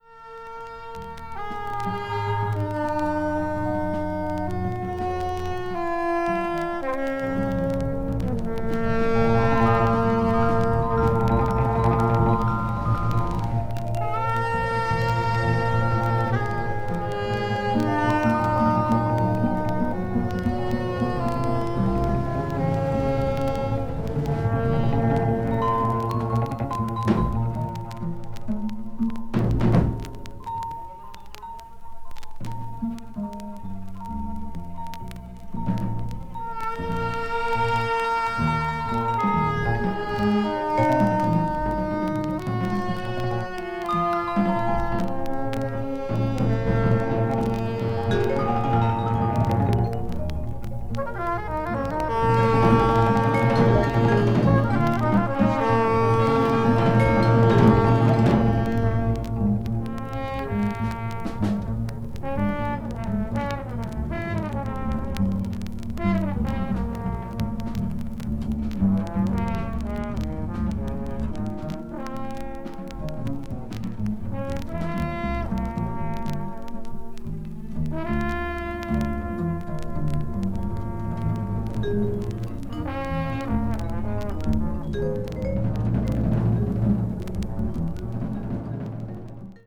media : VG+/VG+(薄いスリキズによるわずかなチリノイズ/軽いチリノイズが入る箇所あり)